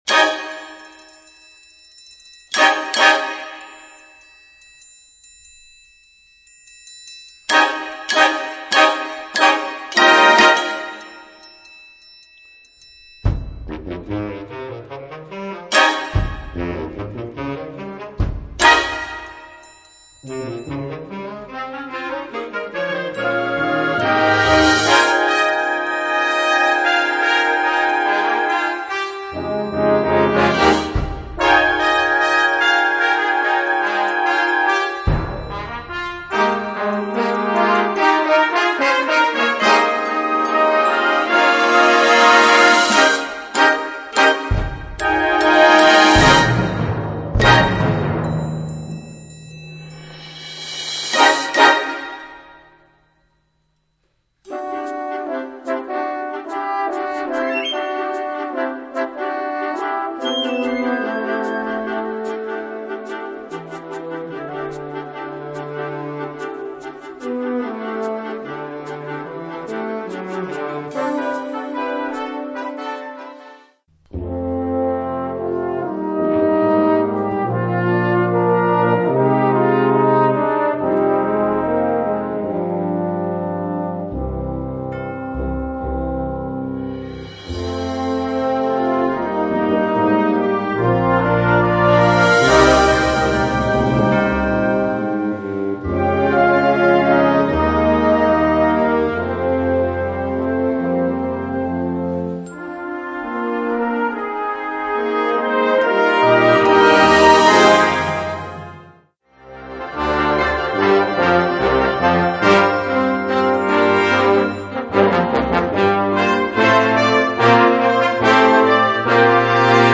Categoría Banda sinfónica/brass band
Subcategoría Música contemporánea original (siglo XX y XXI)
Instrumentación/orquestación Ha (banda de música)